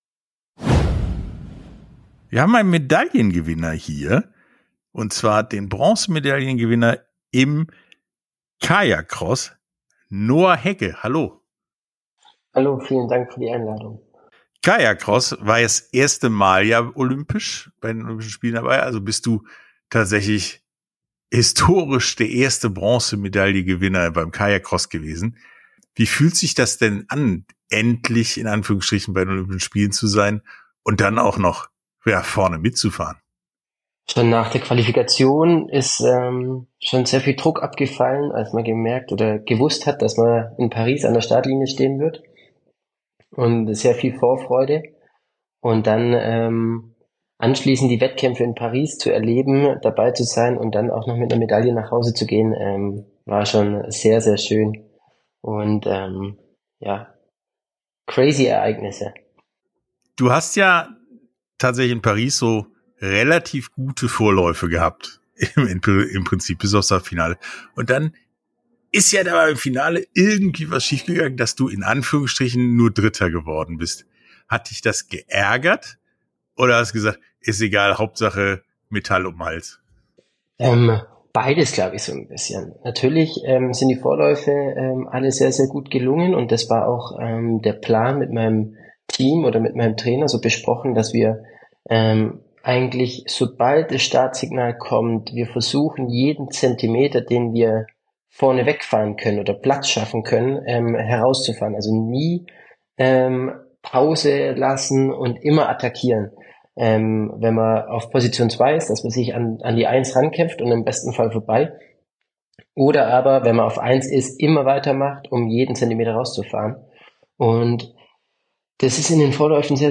Sportstunde - Interview